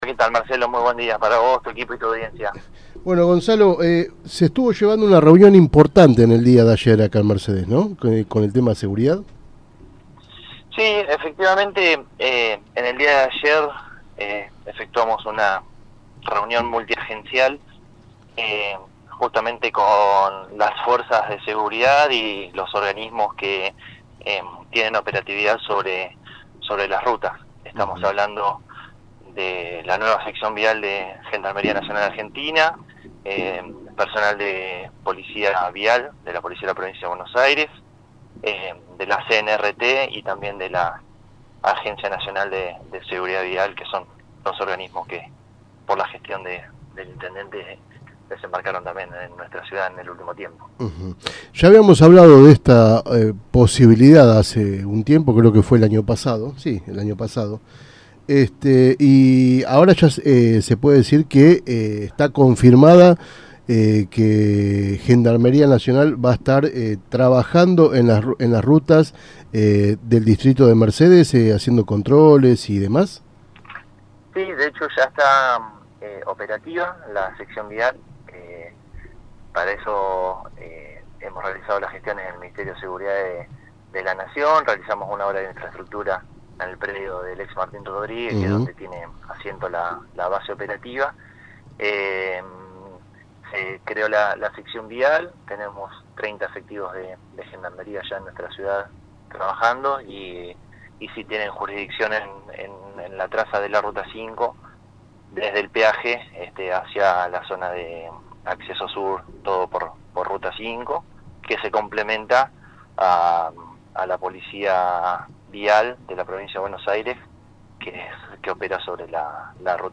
La entrevista se desarrolló en la mañana de hoy, en la 103.5 Mhz, FM La Tribuna.